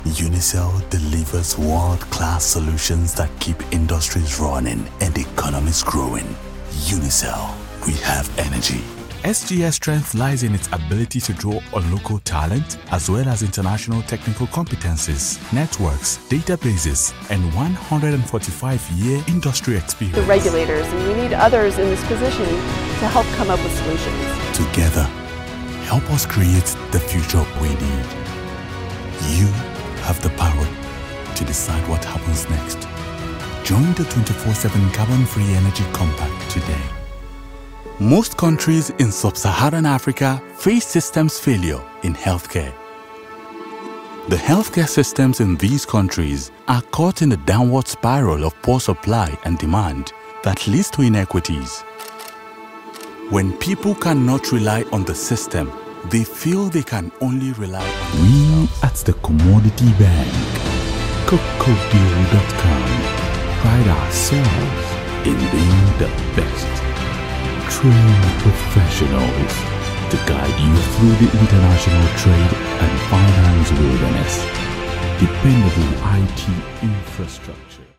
Natürlich, Zuverlässig, Warm, Kommerziell, Vielseitig
Unternehmensvideo
He has an authentic, articulate and clear voice which resonates with audiences across the globe.